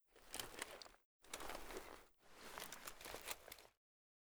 fn57_inspect.ogg